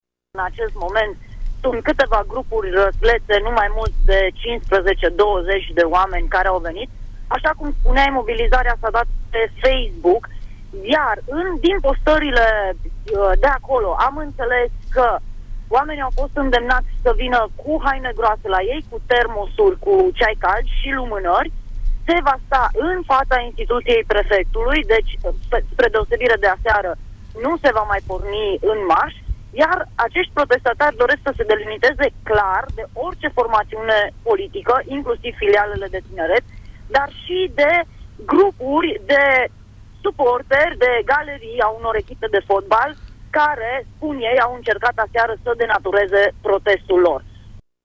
Ei scandează “Nu vrem catedrale, vrem spitale”.